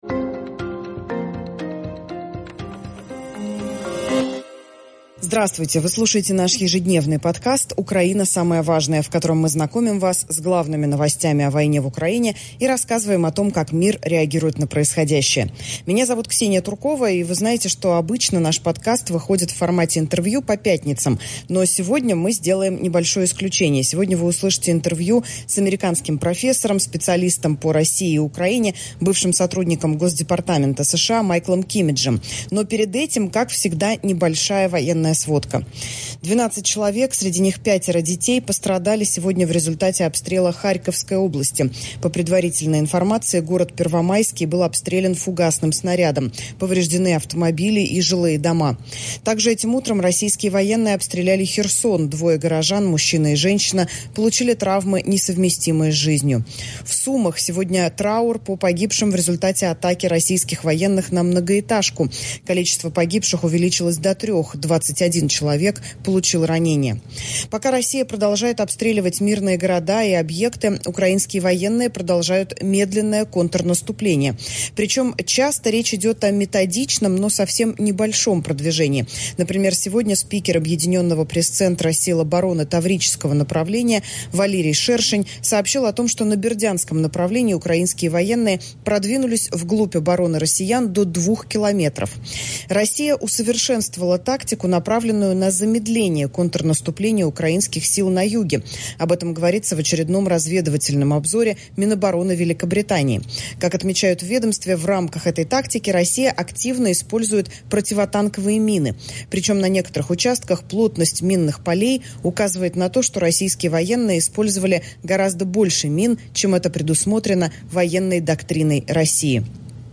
ИНТРО Сегодня новостной подкаст «Украина. Самое важное» выходит в формате интервью.